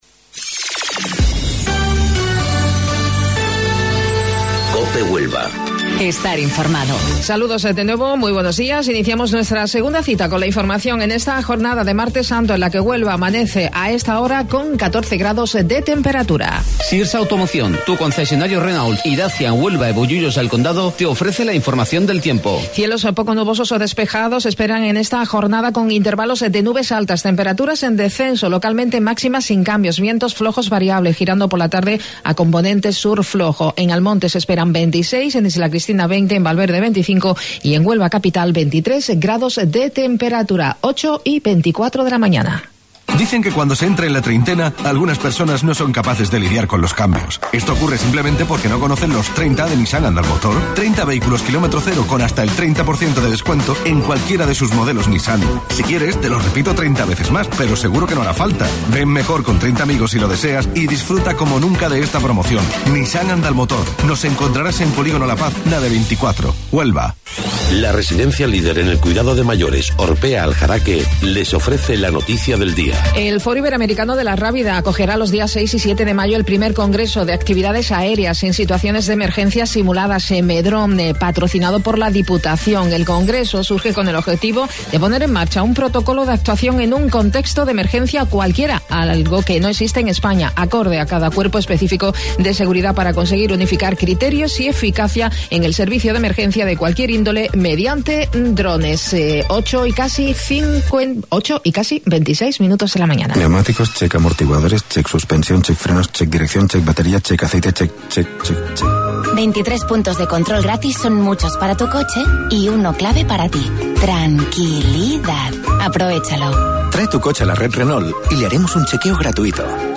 AUDIO: Informativo Local 08:25 del 16 de Abril